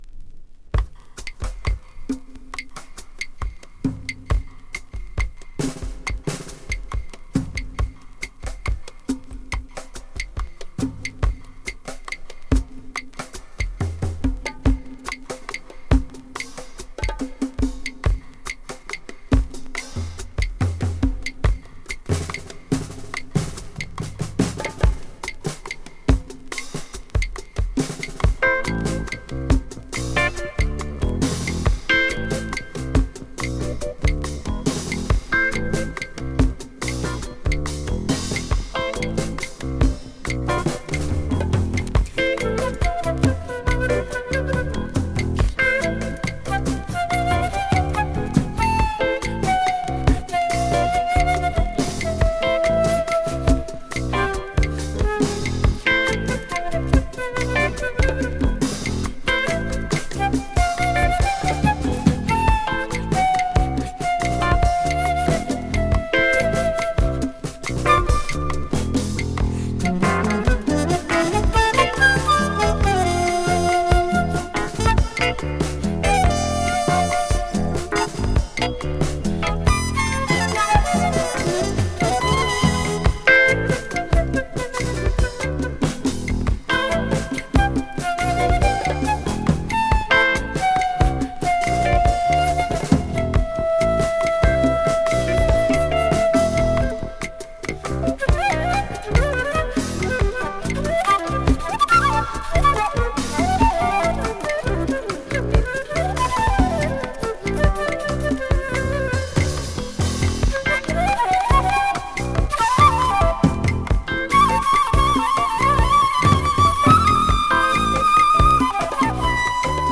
American Indie Fusion!
【FUSION】【JAZZ VOCAL】